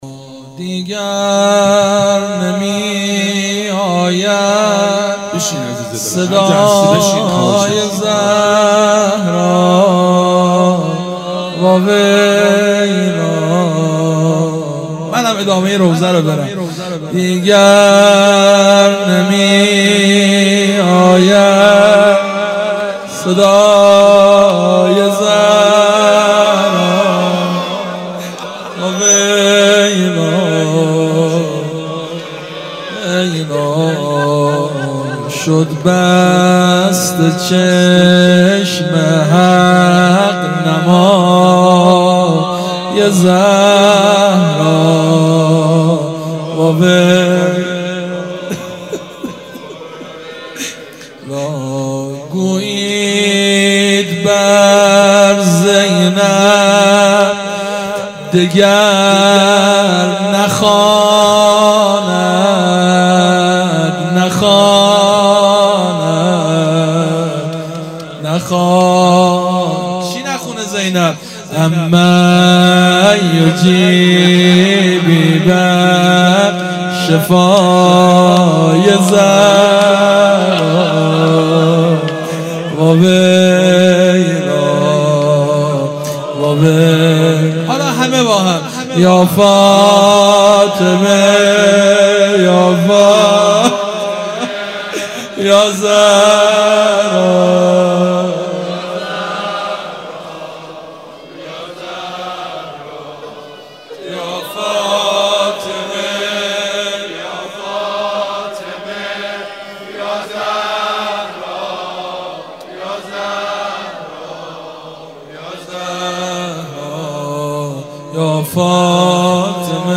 هیئت مکتب الزهرا(س)دارالعباده یزد
0 0 روضه